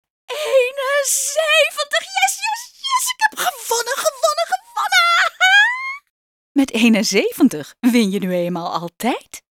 Hieronder wat stemvoorbeelden